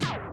Index of /90_sSampleCDs/Zero-G Groove Construction (1993)/Drum kits/Euro techno/Hits & FX